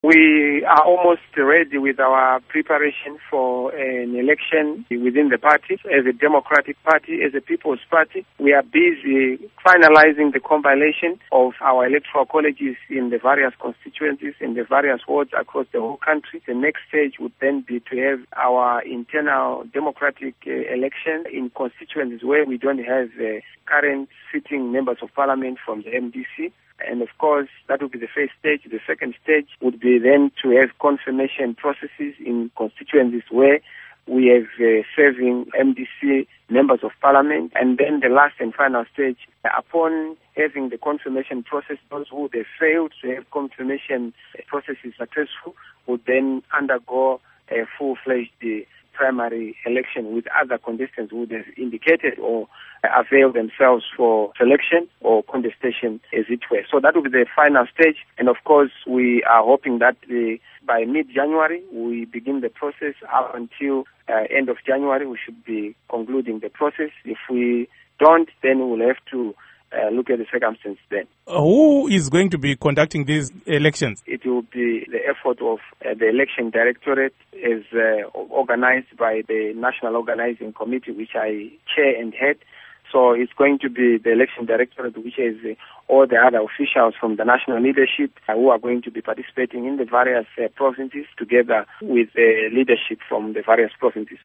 Interview With Nelson Chamisa